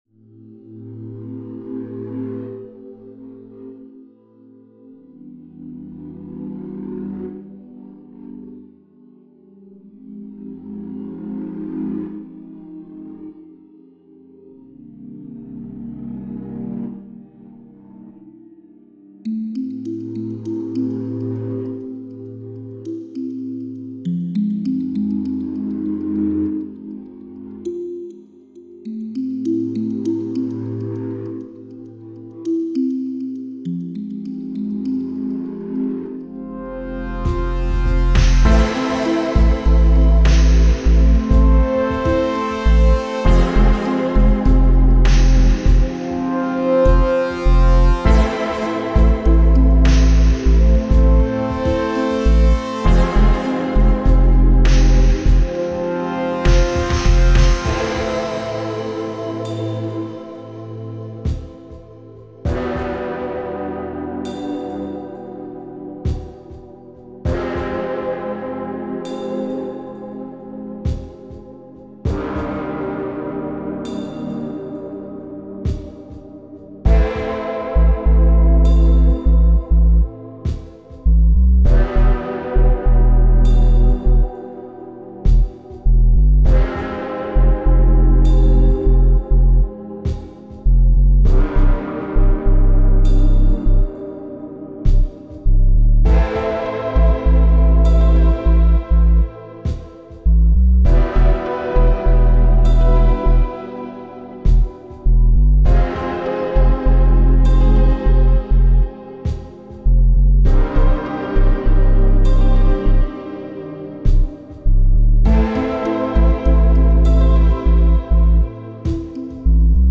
ambient music for a game, can be used when it's raining